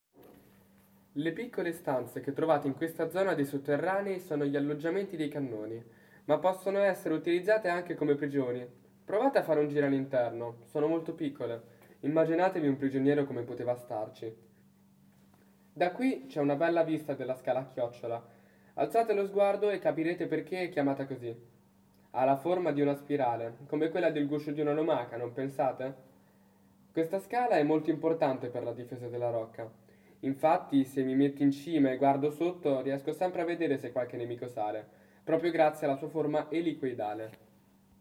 Audioguida 0-12
Ascolta Riccardino, il cavaliere della Rocca